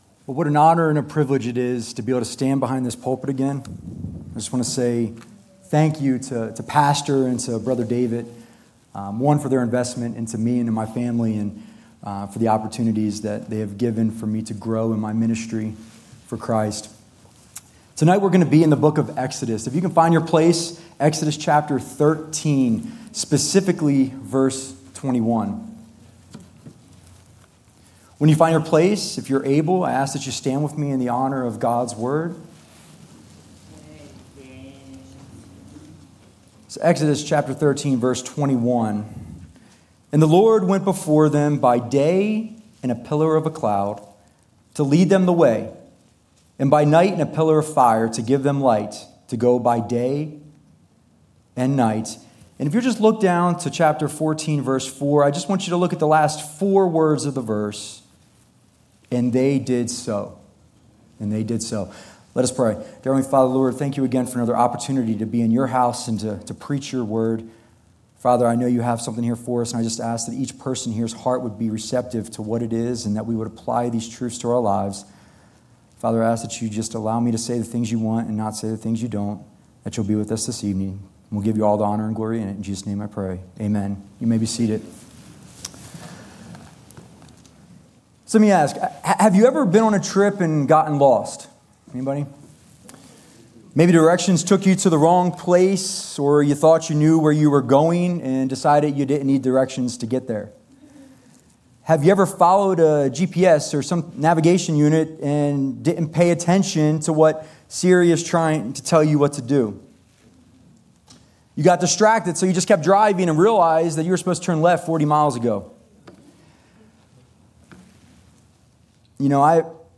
" Guest & Staff Preachers " Guest & Staff Preachers at Bethany Baptist Church Scripture References: Exodus 13:21